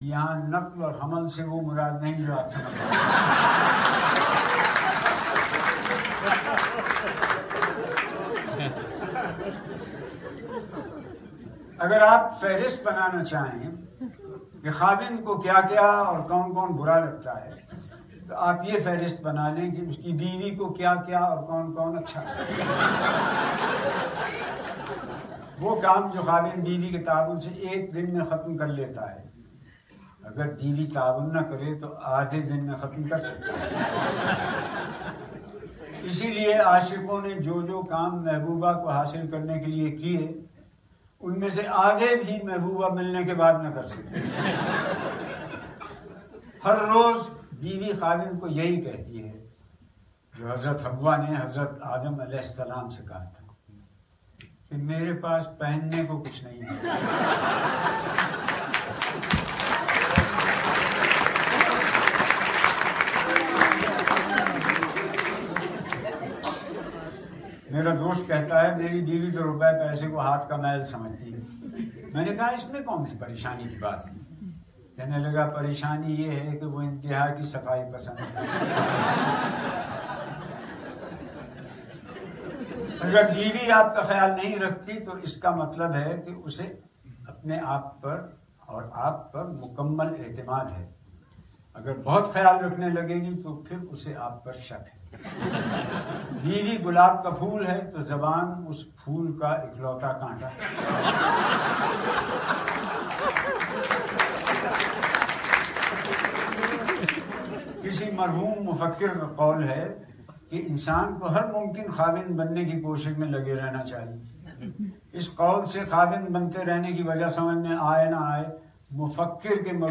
Zia Mohyeddin Reads hilarious piece from Literature
His long career spans acting, directing, writing, broadcasting and a wide range of aesthetic disciplines. Zia Mohyeddin is reading this interesting article in Urdu International Australia function.